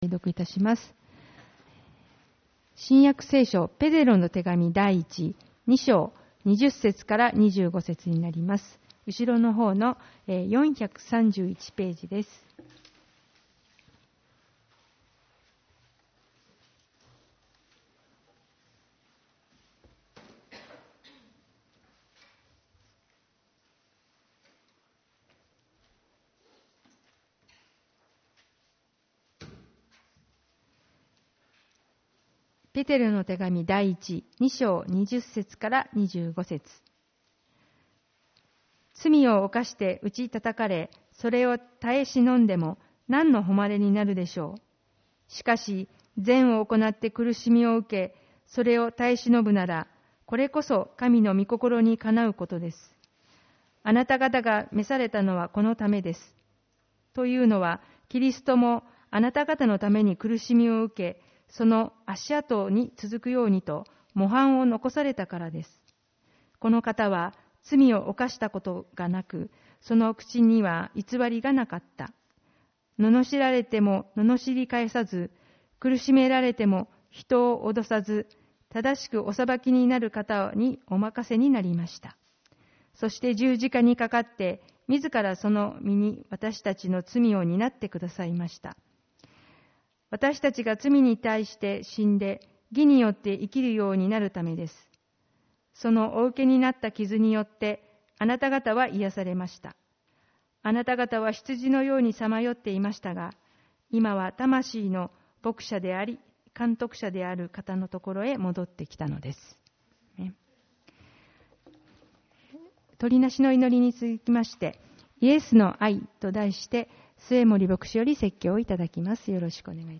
主日礼拝 「イエスの愛」